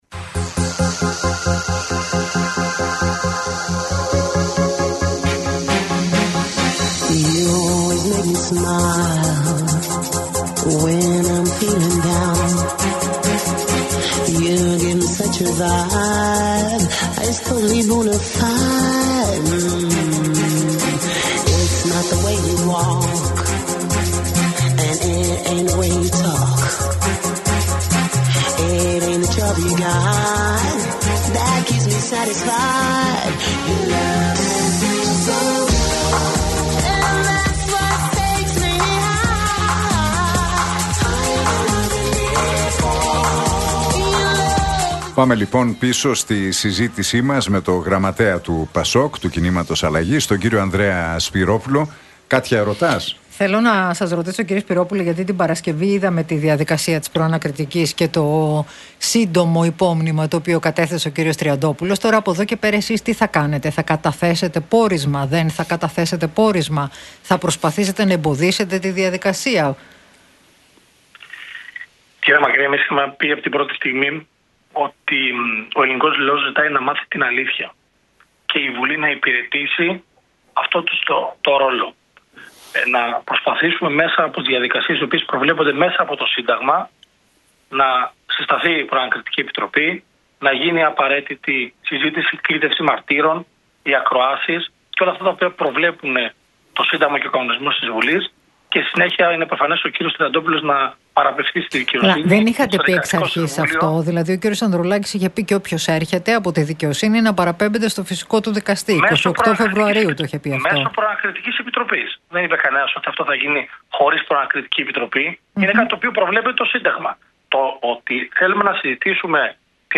Ακούστε την εκπομπή του Νίκου Χατζηνικολάου στον ραδιοφωνικό σταθμό RealFm 97,8, την Δευτέρα 31 Μαρτίου 2025.